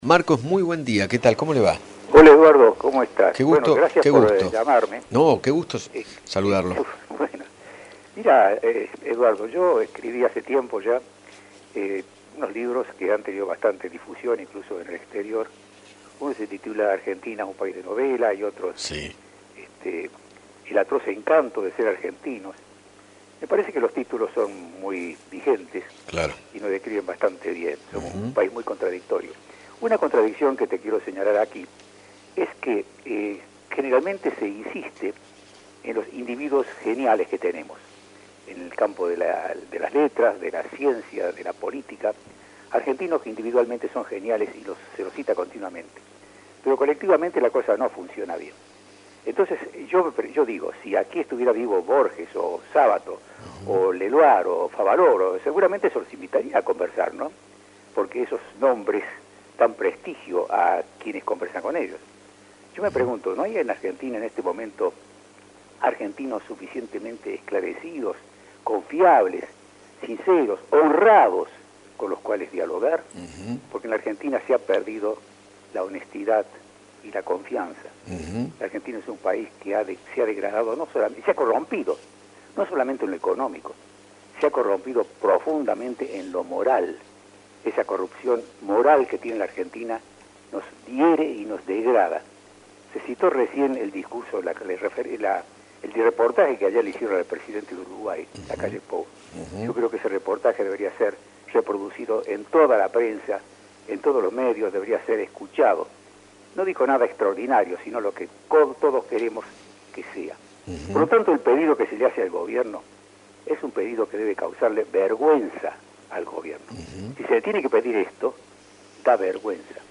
Marcos Aguinis, médico neurocirujano y escritor, dialogó con Eduardo Feinmann sobre la gestión de Alberto Fernández y se refirió al lugar que ocupa Cristina Fernández. Además, reflexionó acerca de la política en Argentina.